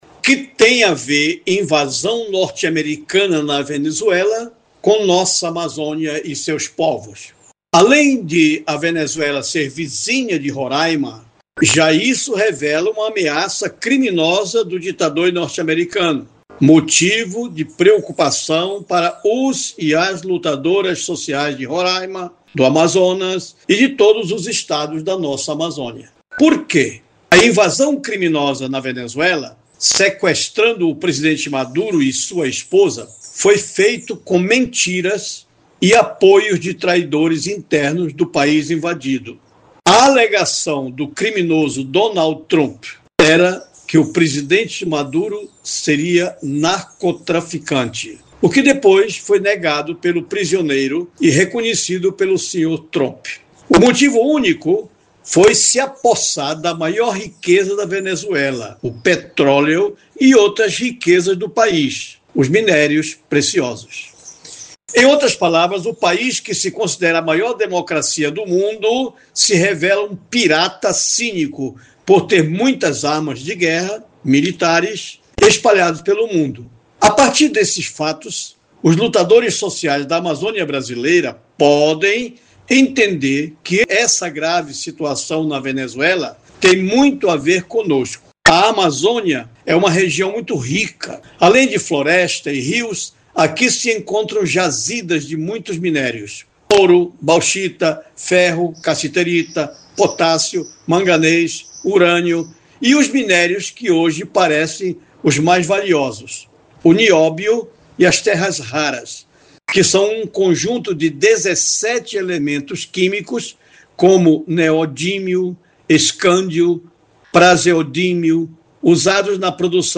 Acompanhe o editorial